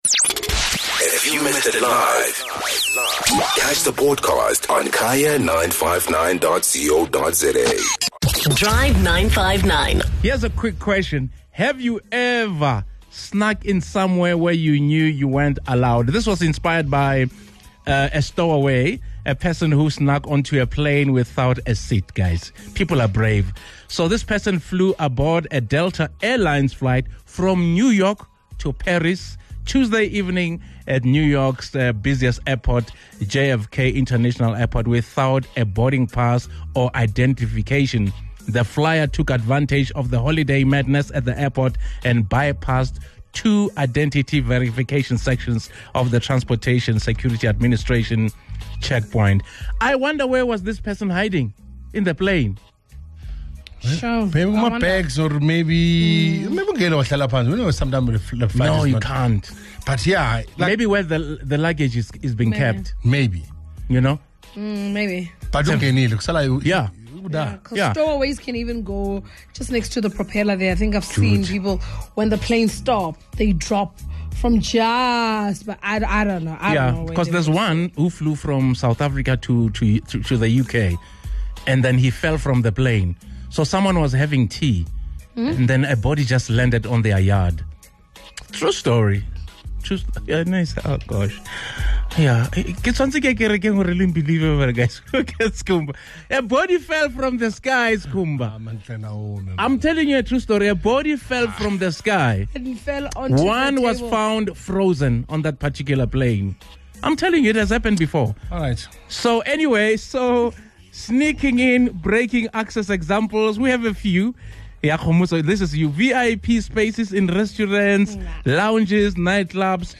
The Drive 959 team hears the stories of listeners who have gone to great lengths, just to be where they wanted to be!